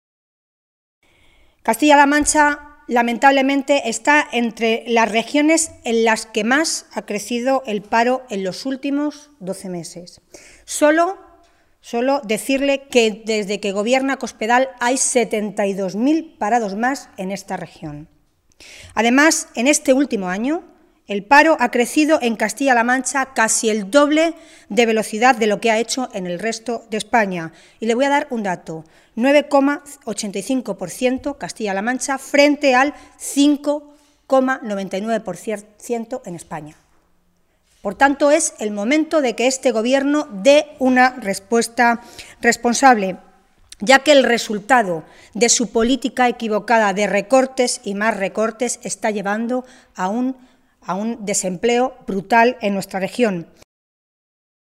Milagros Tolón, portavoz de Empleo del Grupo Parlamentario Socialista
Cortes de audio de la rueda de prensa